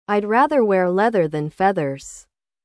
Frases para praticar o TH sonoro (ð):
3. I’d rather wear leather than feathers. (aɪd rɑːðər wɛr lɛðər ðæn fɛðərz)